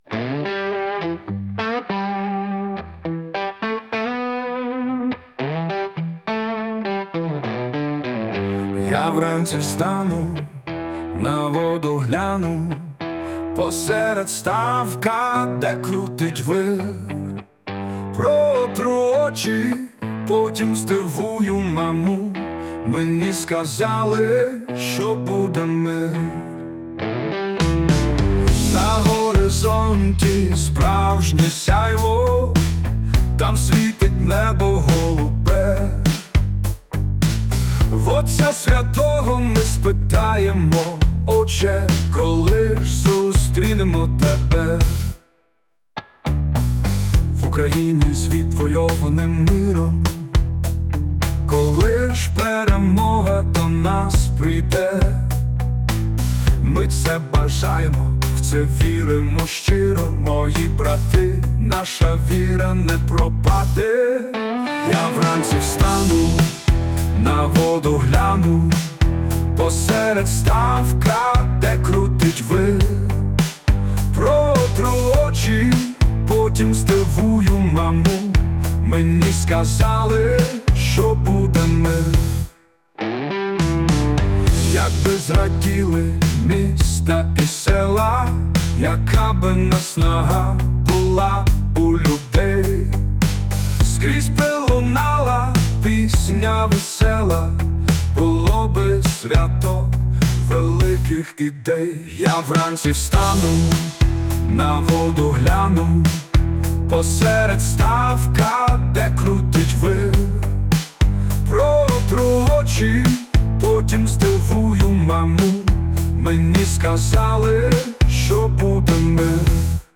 ТИП: Пісня